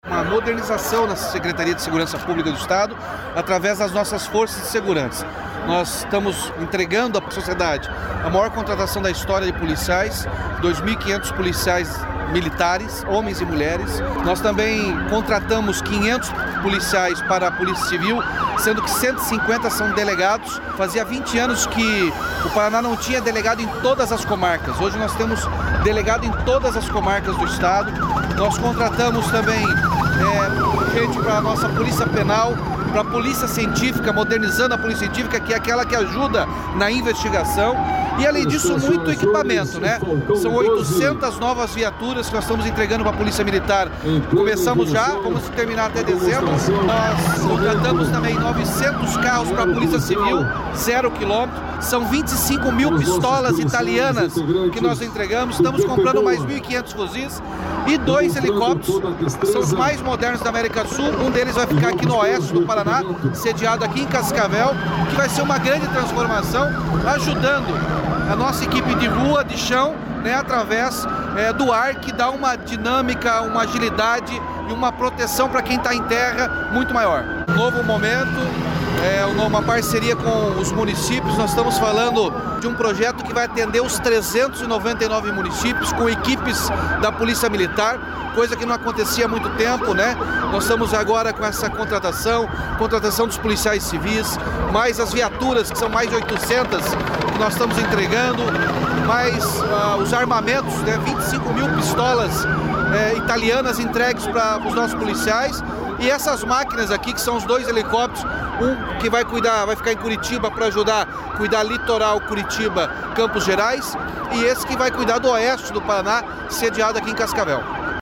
Sonora do governador Ratinho Junior sobre a formatura de 319 policiais militares em Cascavel